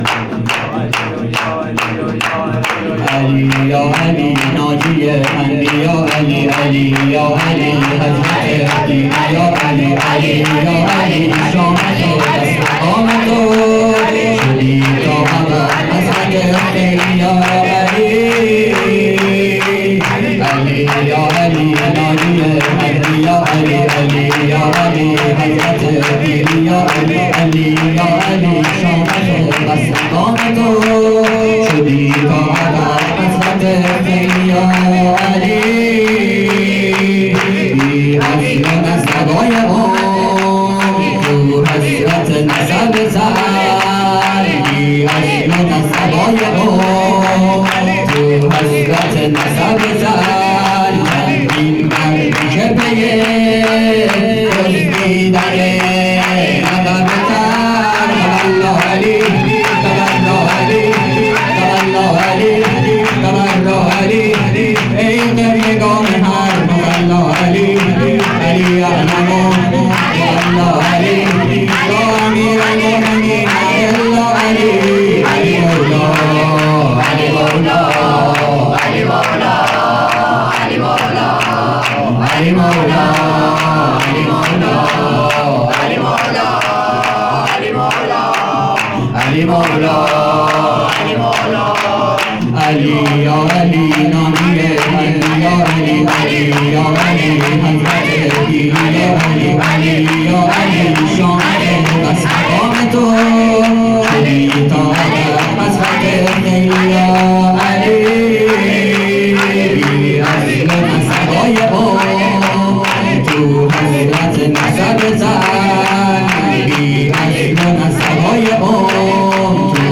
سرود امیرالمومنین امام علی(ع)